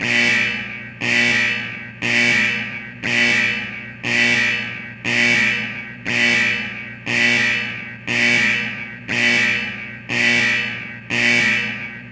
alarmKlaxonLoop.ogg